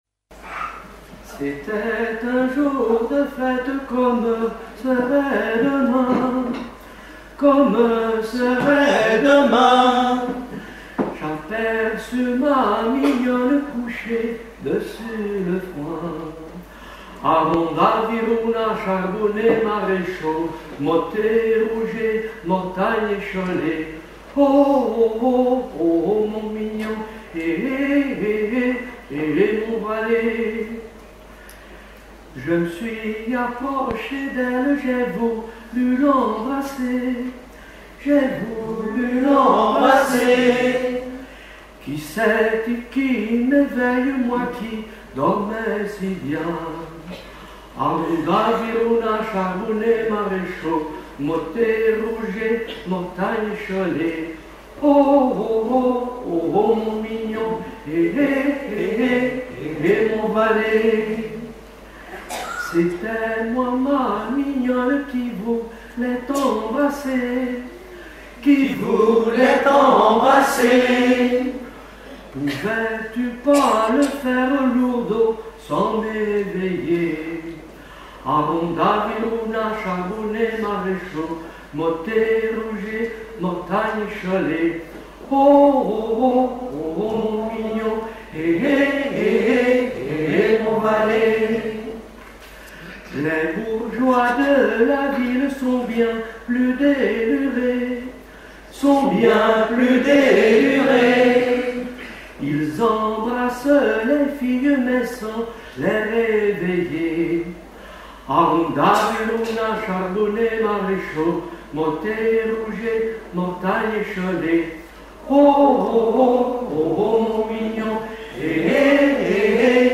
Genre laisse
Festival de la chanson pour Neptune F.M.
Pièce musicale inédite